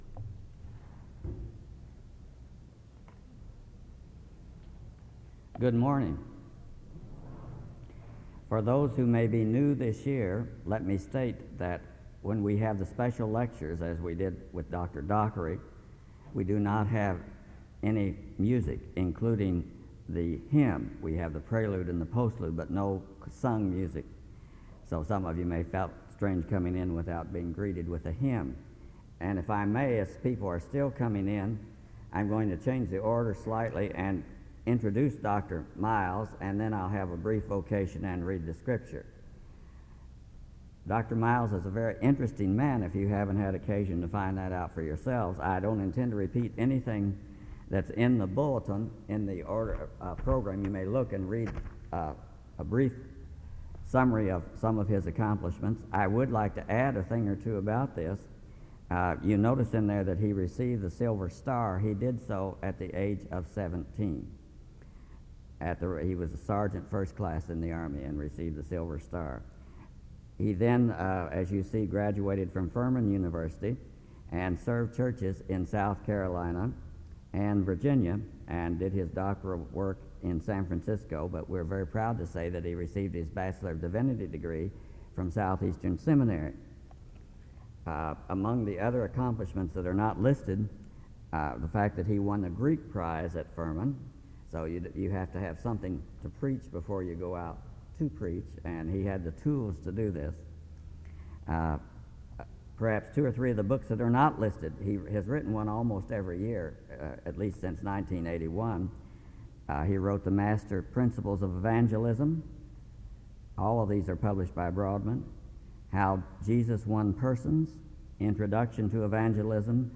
SEBTS Faculty Lecture